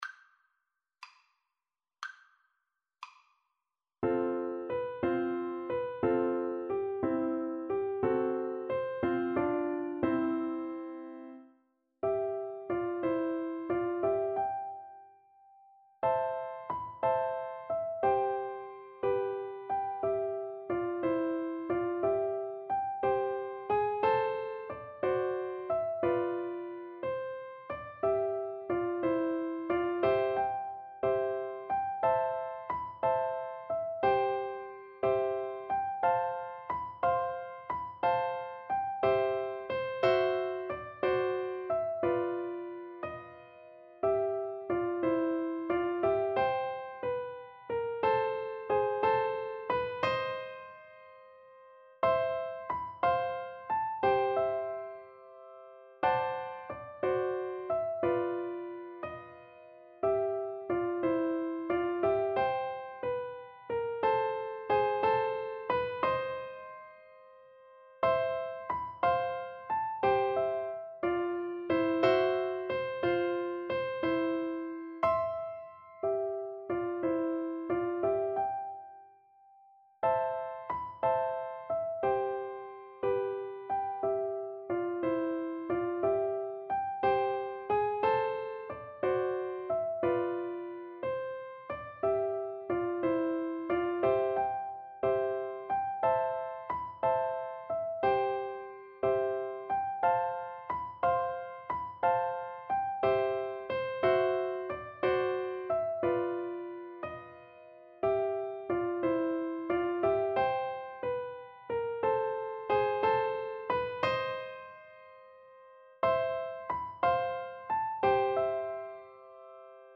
6/8 (View more 6/8 Music)
Gently and with expression .
Piano Duet  (View more Intermediate Piano Duet Music)
Traditional (View more Traditional Piano Duet Music)